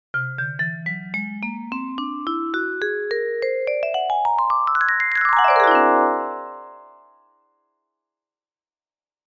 ホールトーンスケール さんの音ですっ！
ド、レ、ミ、ファ＃、ソ＃、ラ＃、そしてド、か…。
はい！全ての音がとっても全音で並んでいるということですっ！
wholetone.mp3